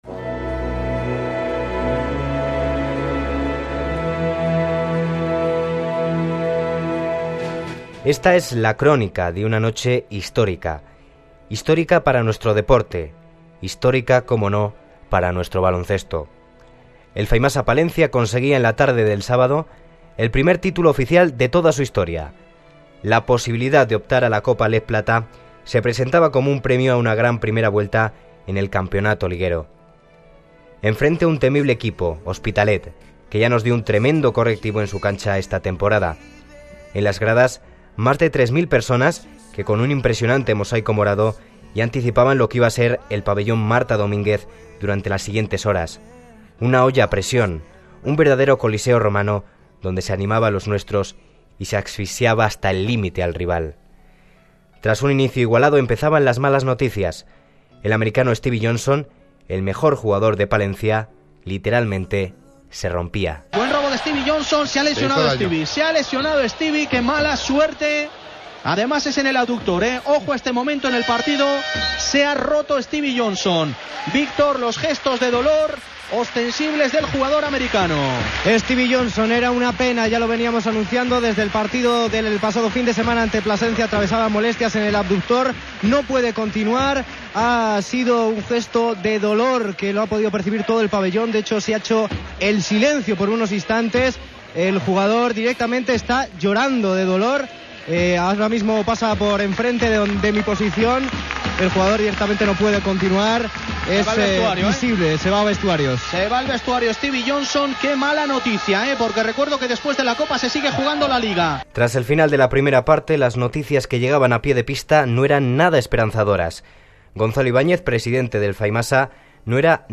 Resumen de la Copa LEB Plata 2009 de Radio Palencia de la Cadena Ser
cadena-ser-final-copa.mp3